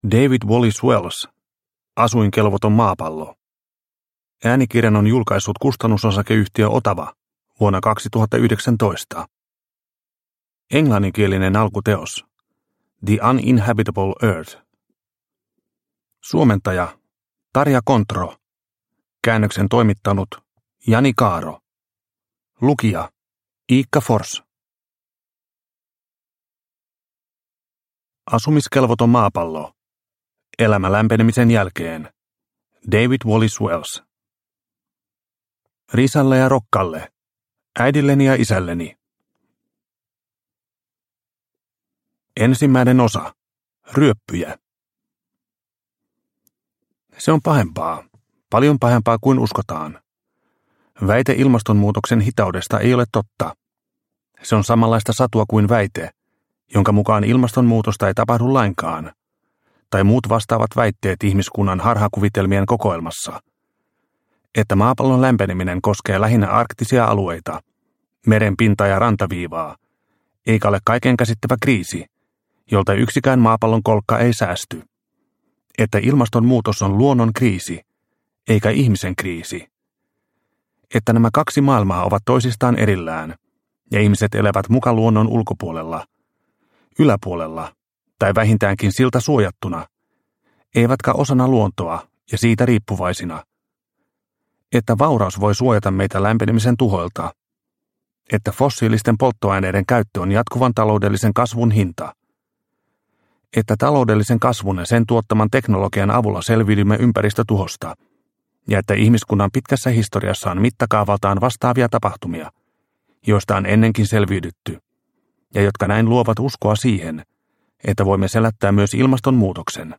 Asumiskelvoton maapallo – Ljudbok – Laddas ner